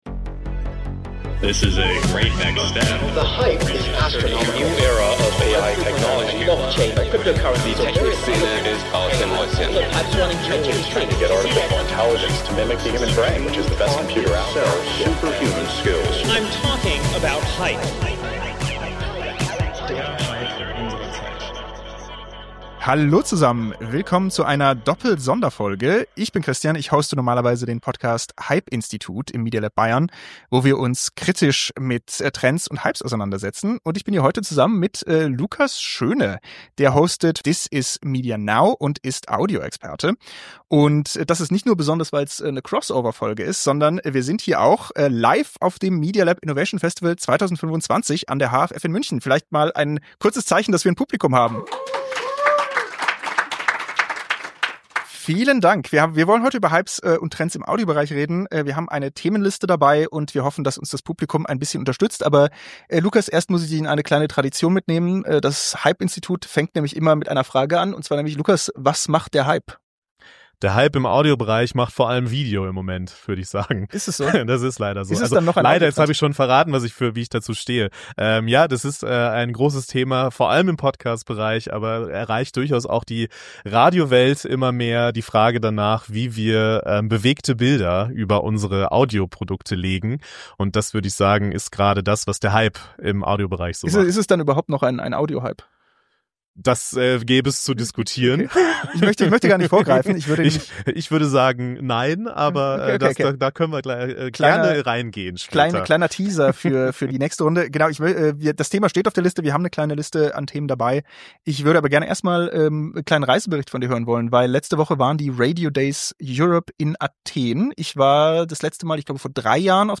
Auf dem Media Lab Innovation Festival 2025 klärten das Hype Institut und der Medienpodcast "This is Media Now" live die Audio-Zukunft.